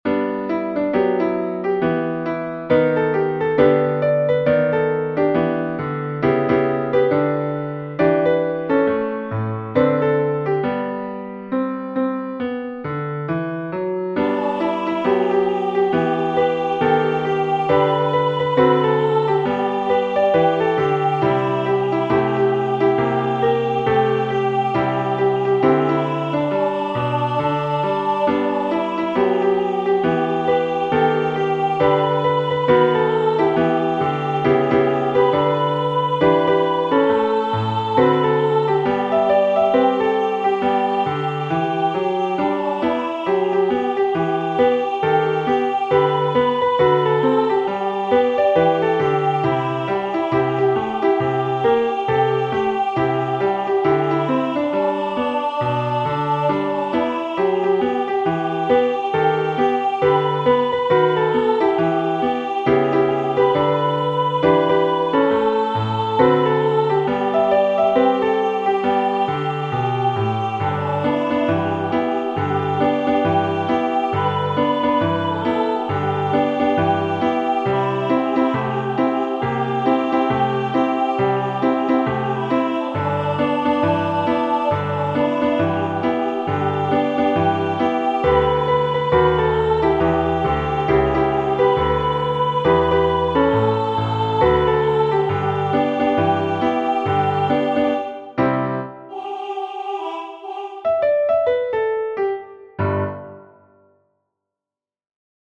This Little Light of Mine Hymn #1028 arranged as a children's song sung in unison.
Voicing/Instrumentation: Primary Children/Primary Solo